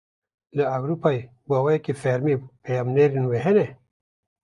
Pronounced as (IPA) /ɛwɾoːˈpɑː/